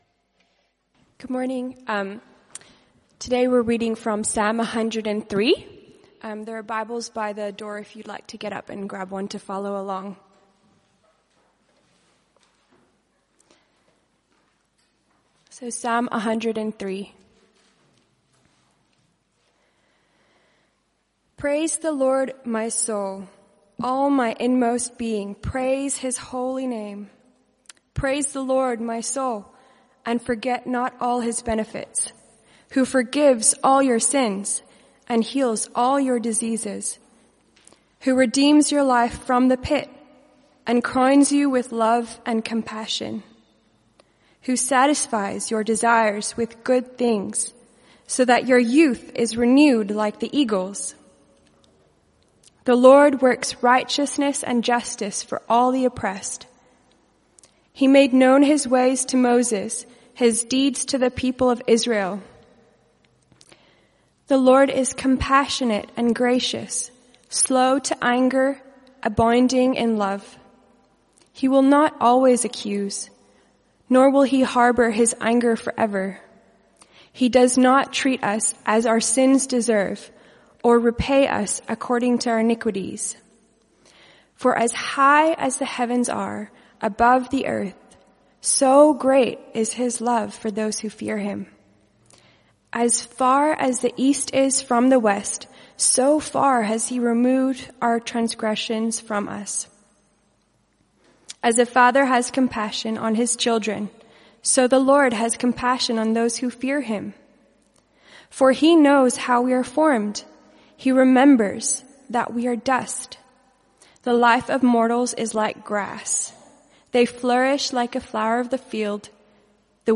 Passage: Psalm 103 Type: Sermons